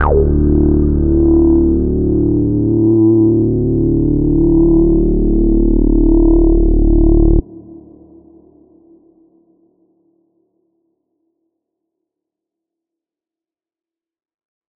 Bass_D_02.wav